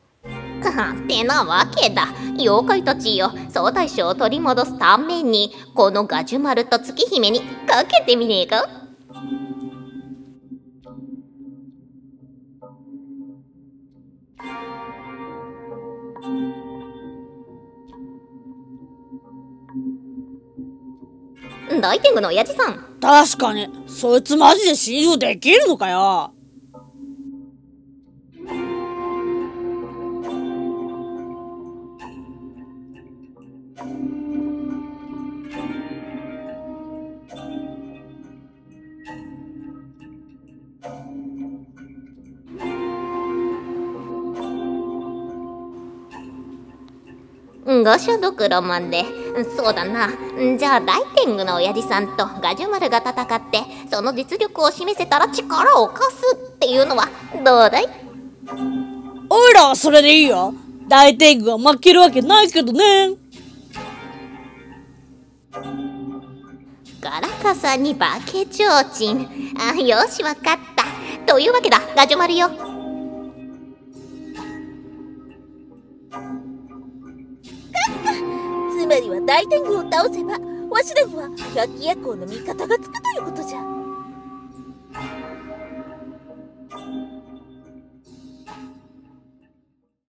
【声劇】妖怪会議【7人声劇】